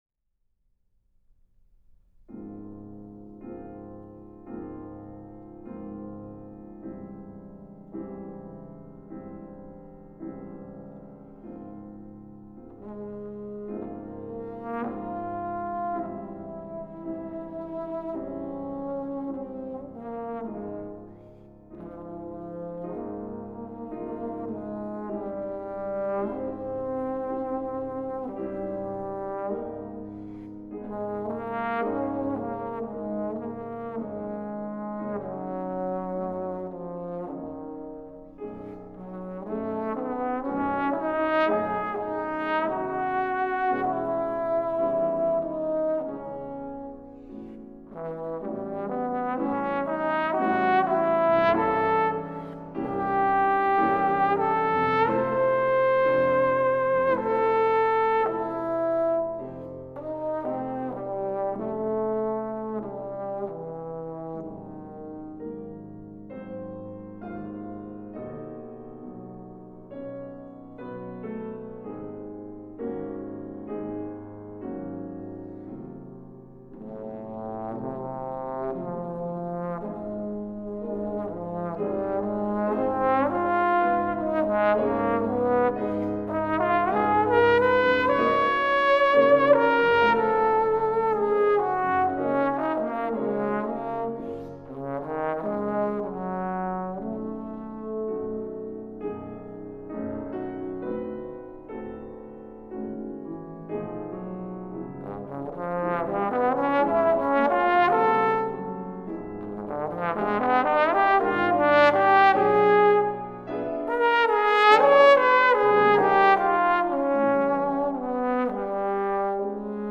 Here is a sound file of me playing the Ballade by Eugene Bozza, a piece which travels through both the high and low ranges: